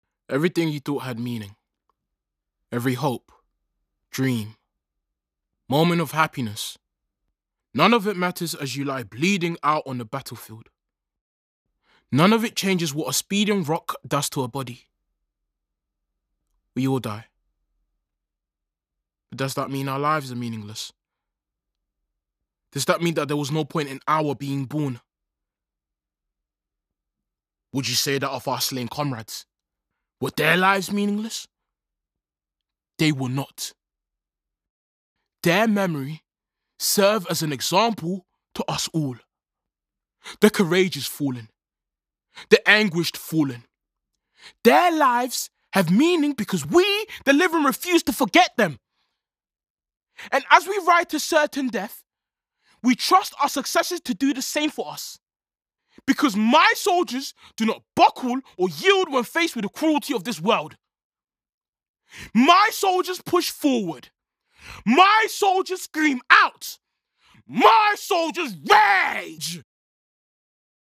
Voice Reel
Drama Reel